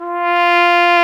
Index of /90_sSampleCDs/Roland LCDP12 Solo Brass/BRS_Cornet/BRS_Cornet 2